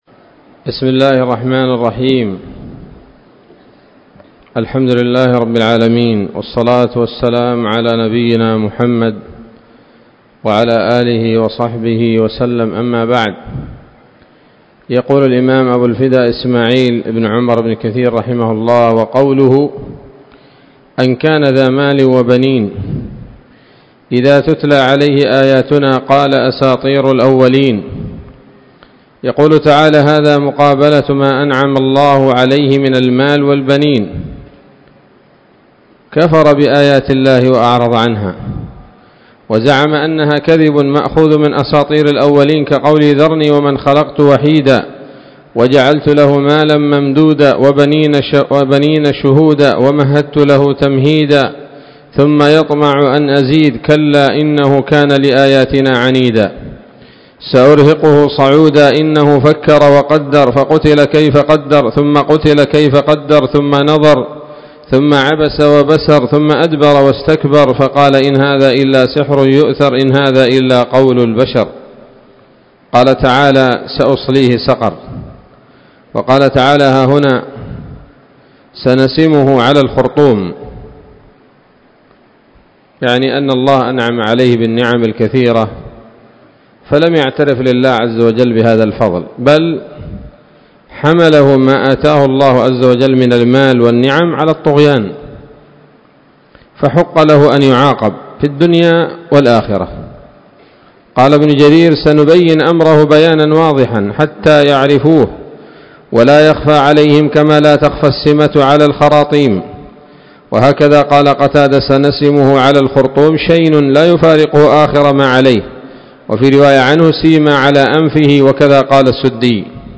الدرس الرابع من سورة القلم من تفسير ابن كثير رحمه الله تعالى